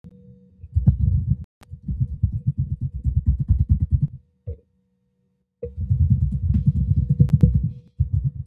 Dźwięk klawiszy i drgań biurka podczas nagrania
Jeśli komputer stoi na blacie lub intensywnie piszemy na klawiaturze, mikrofon potrafi „złapać” te drgania.